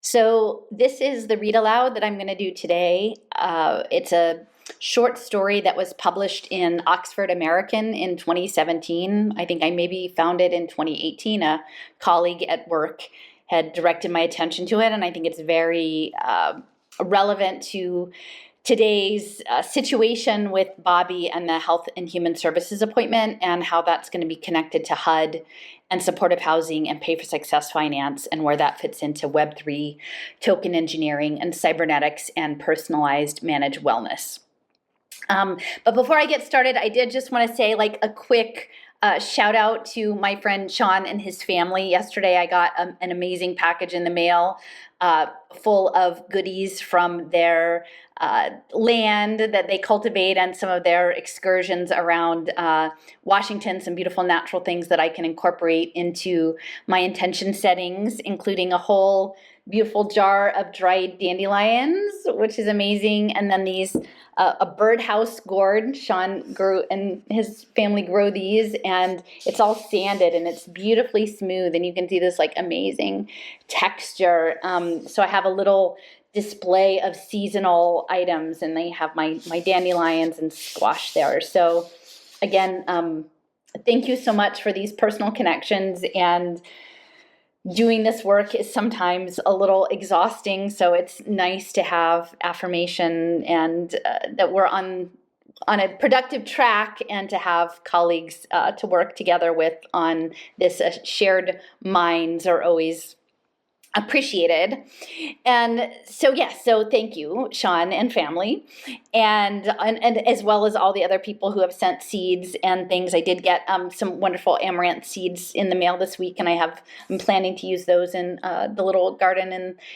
HMO Financed Smart Housing, Short Story Read Aloud and Commentary